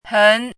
chinese-voice - 汉字语音库
hen2.mp3